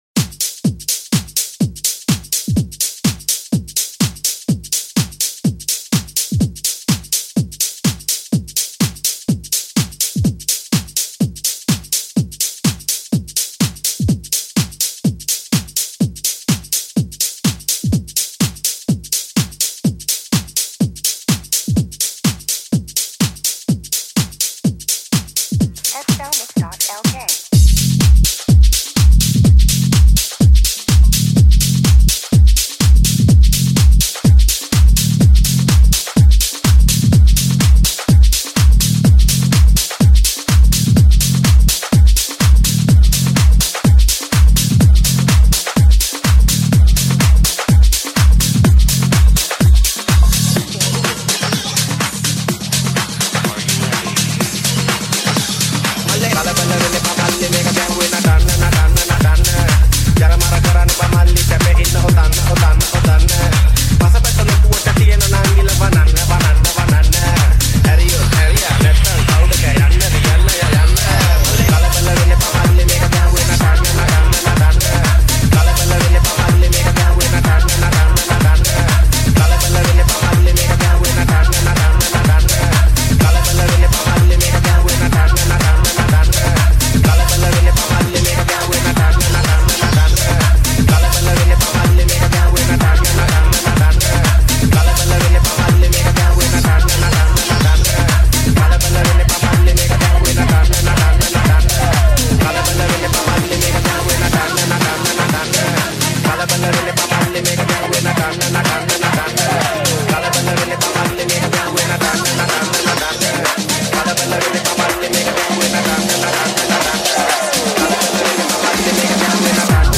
Tech House Remix